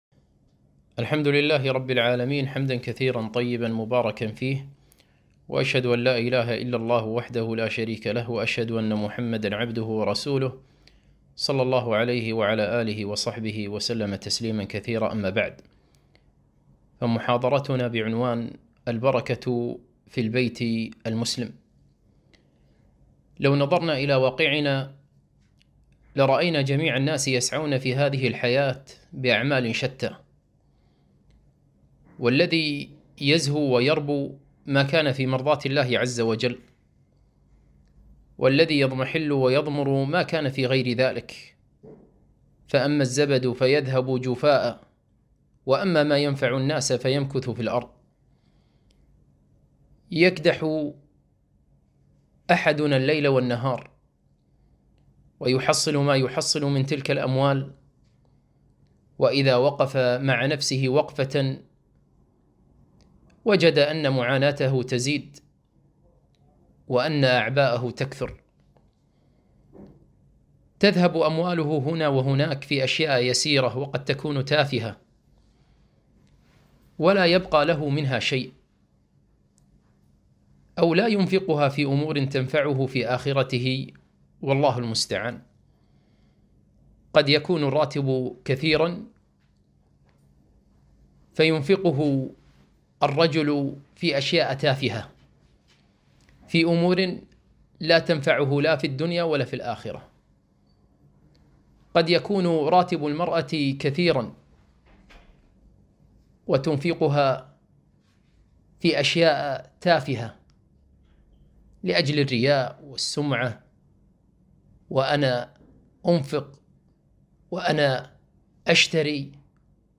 محاضرة - البركة في البيت المسلم